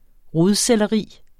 Udtale [ ˈʁoð- ]